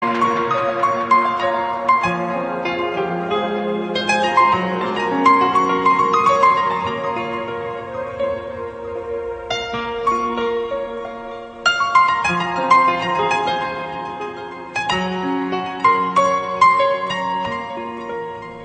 Романтическая мелодия, очень трогательная и красивая Ура!